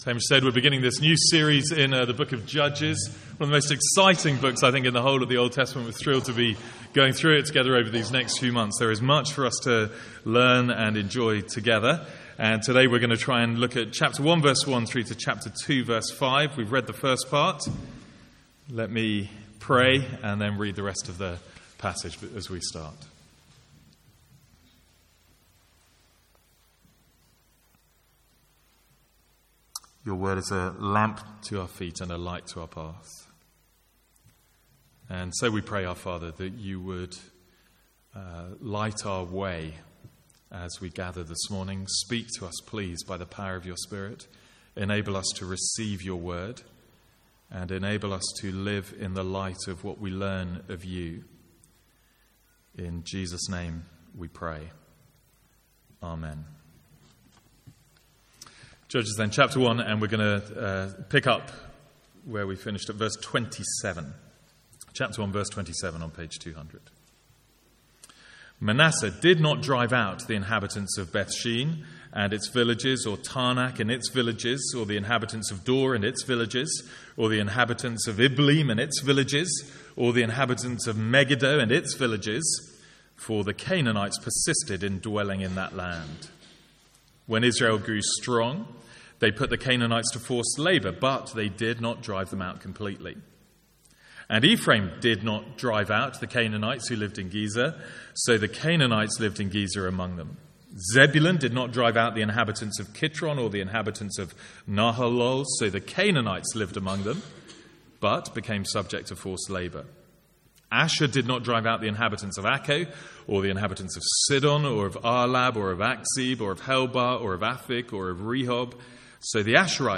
From the Sunday morning series in Judges.
Download Download Reference Our sermon series in Judges from 2016.